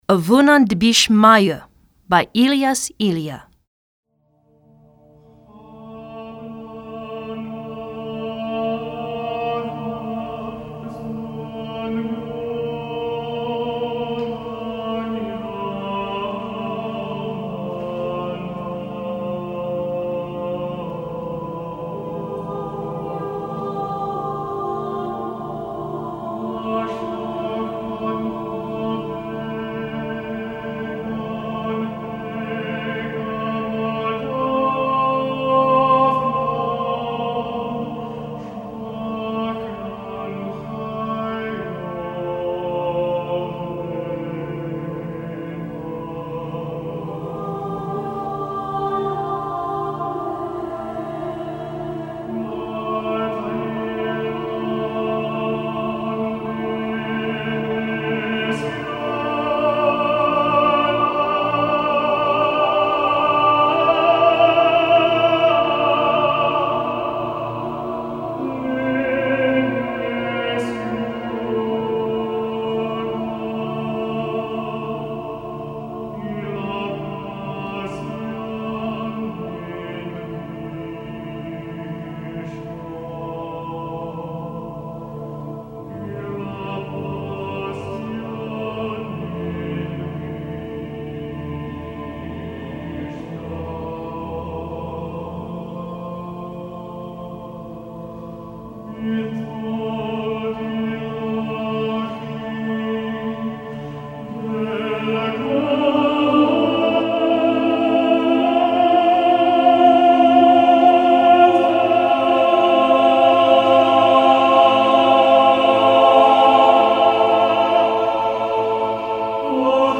Choeur Mixte SATB a Cappella